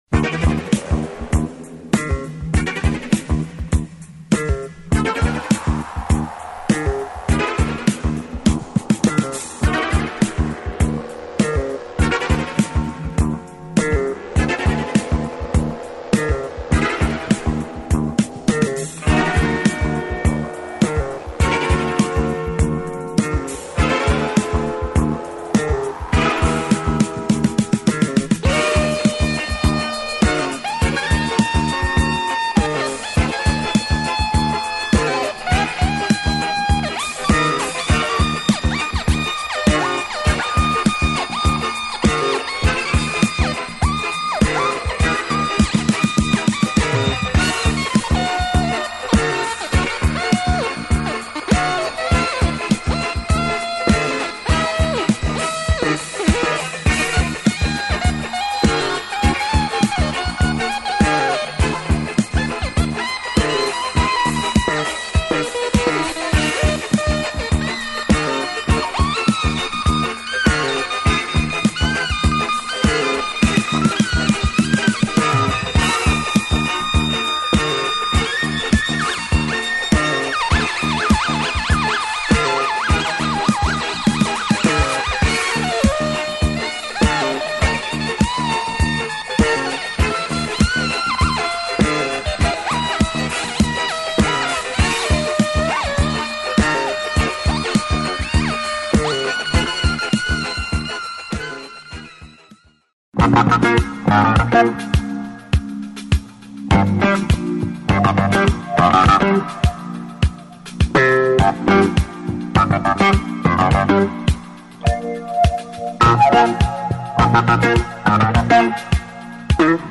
Amazing soundtrack for this German TV cop show !
drums
guitar. Spacey downtempo breaks
dark electro breaks